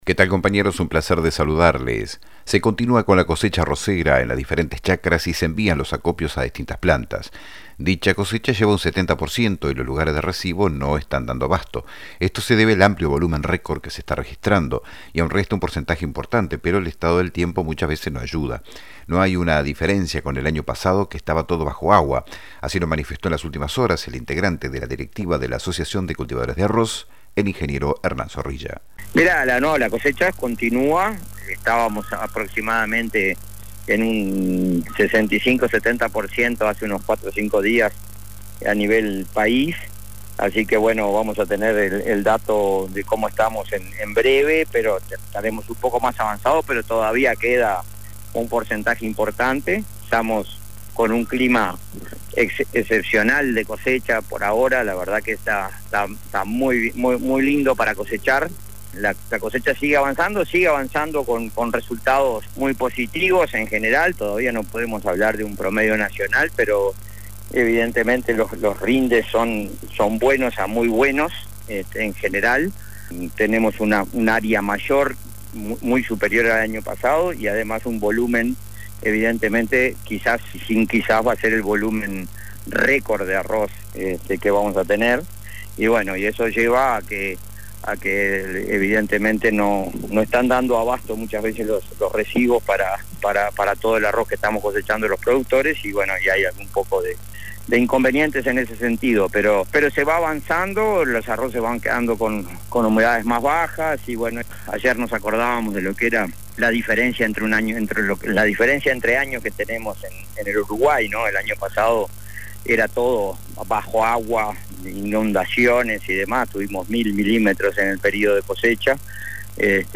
Informe: